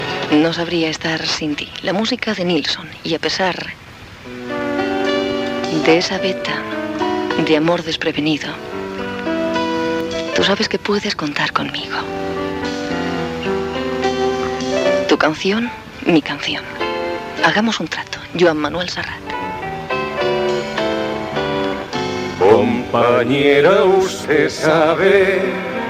Presentació d'un tema musical.
Musical
FM